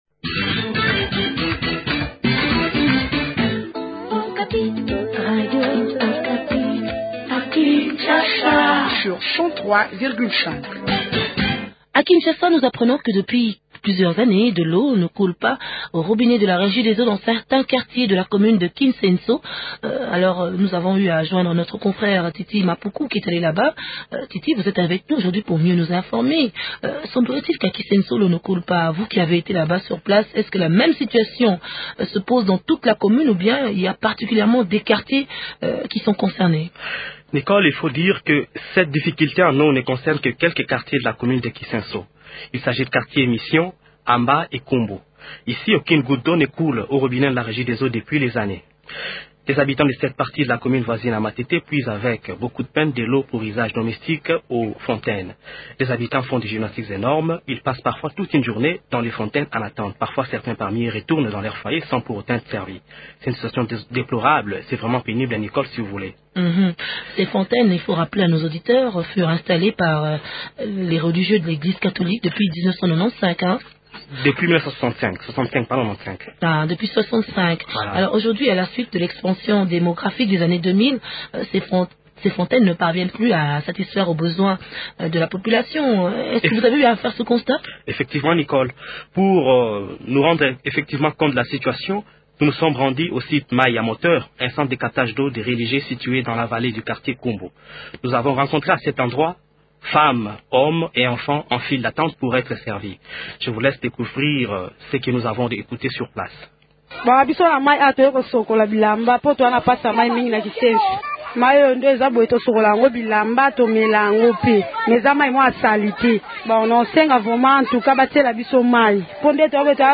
entretient